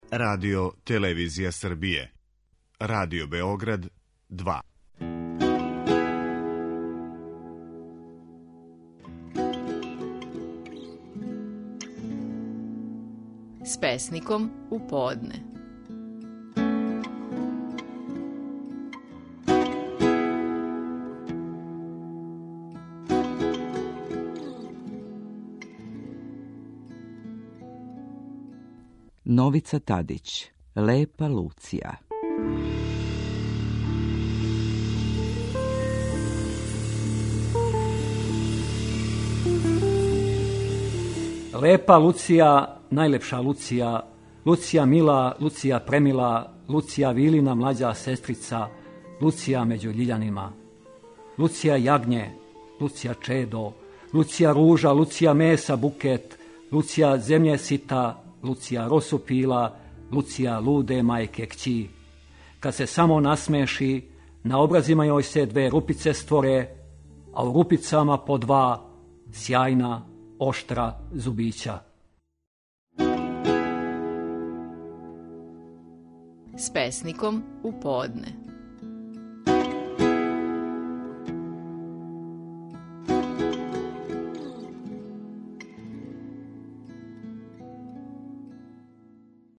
Стихови наших најпознатијих песника, у интерпретацији аутора.
Новица Тадић говори песму „Лепа Луција".